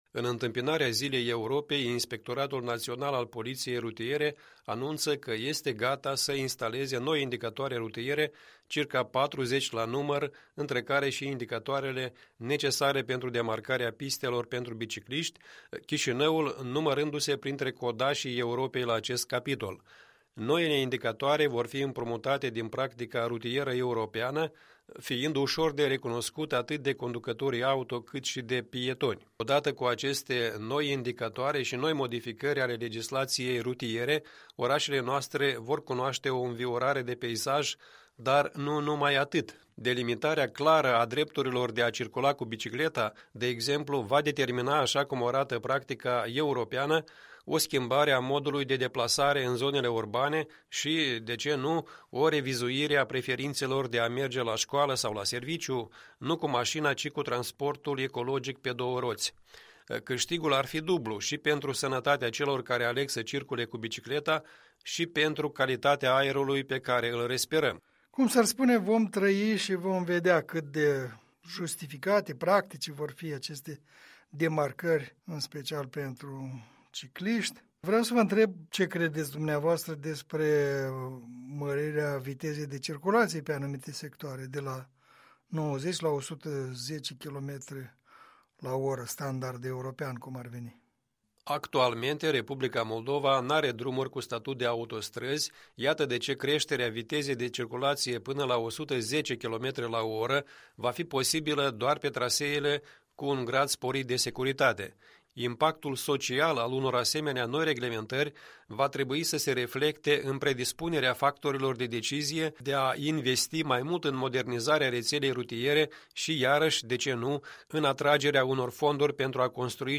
Un punct de vedere săptămînal în dialog.